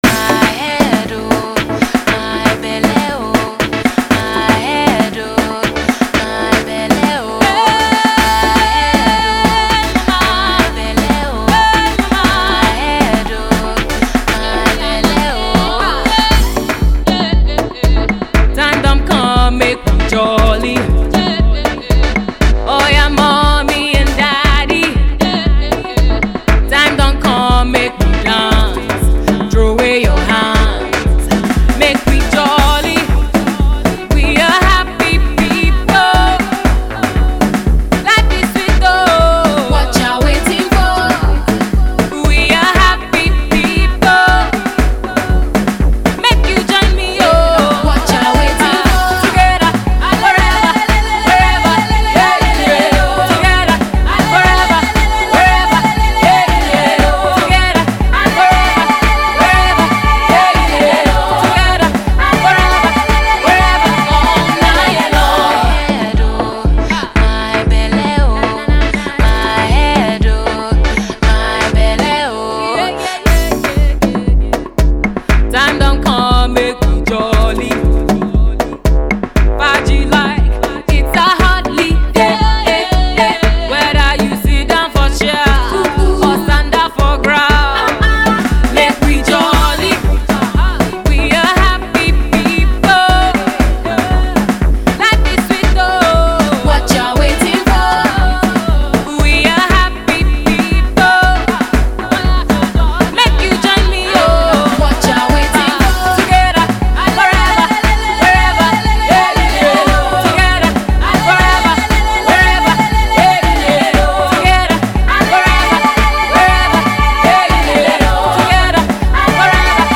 tribal Afro-House Fusion
It’s hot and heavy.